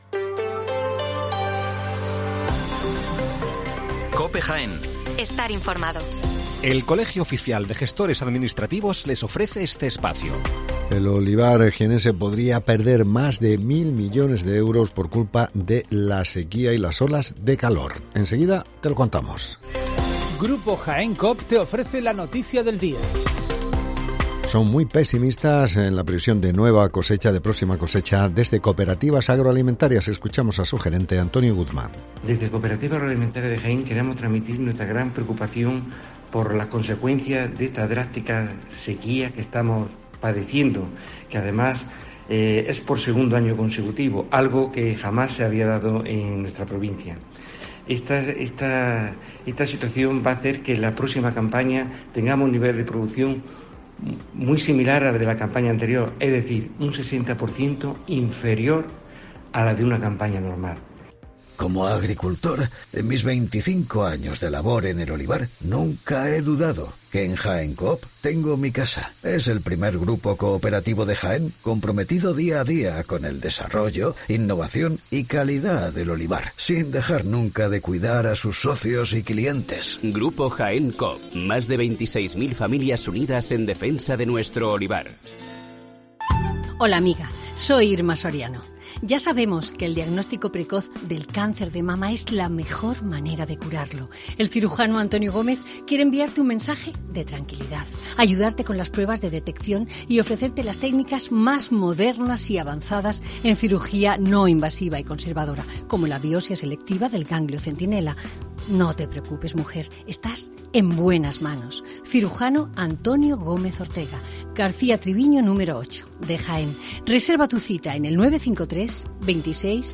HERRERA EN COPE Las noticias locales